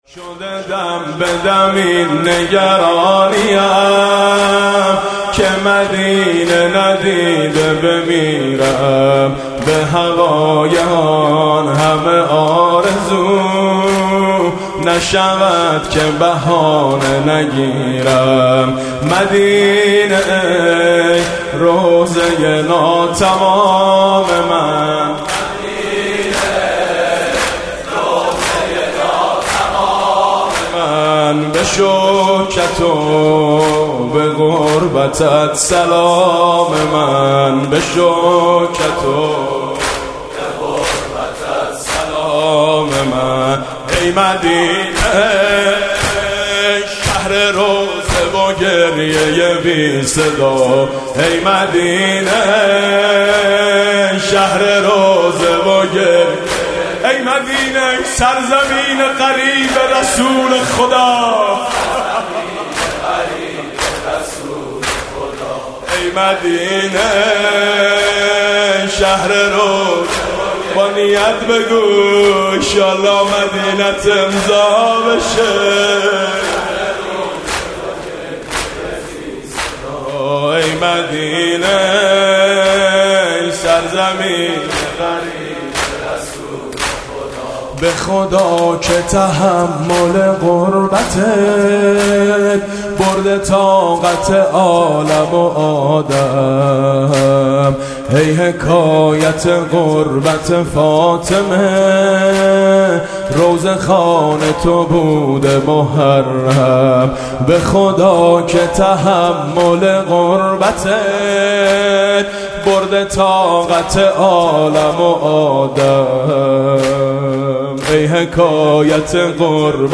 «شهادت امام حسن 1393» زمینه: سلام من به چهار امام بی حرم